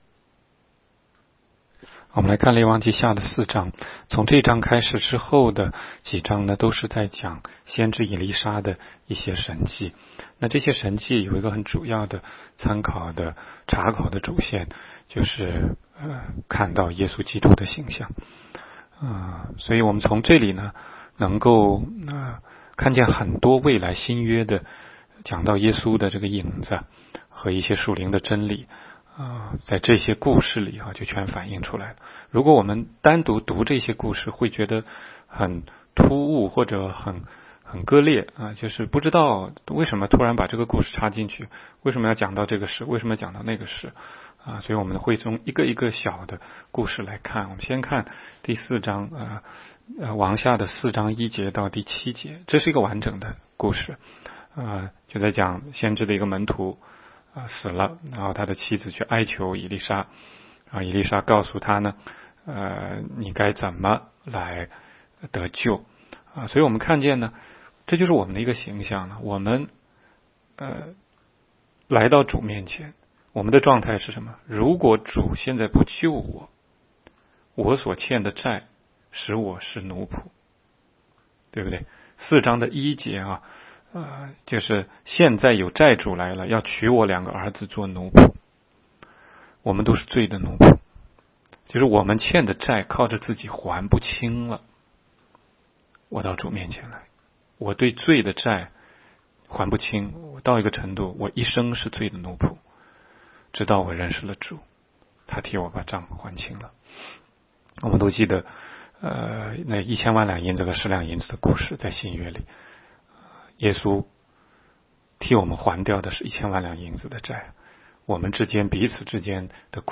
16街讲道录音 - 每日读经-《列王纪下》4章